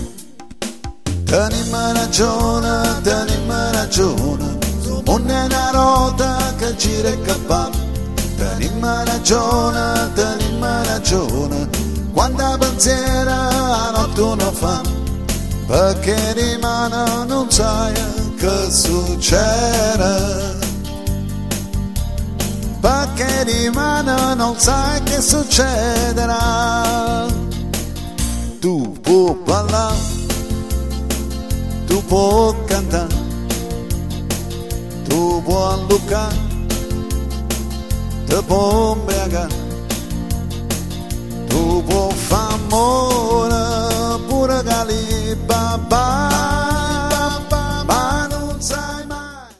Commedia Comica - Musicale